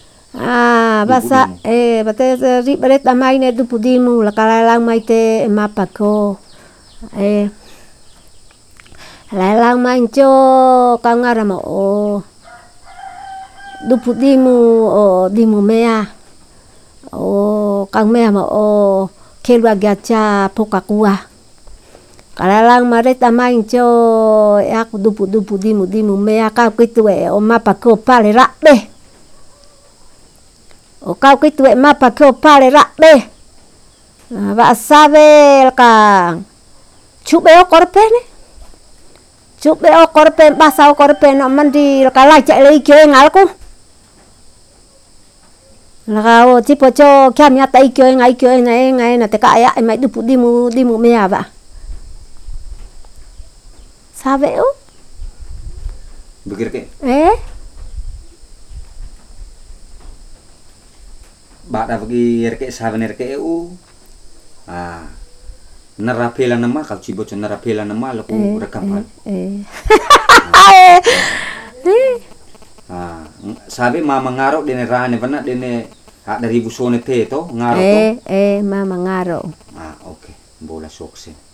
Narrator
(The setting of the recorder was faulty at the time, so it is rather loud or noisy.
Recording made in kampong Ko'a, Ko'a domain.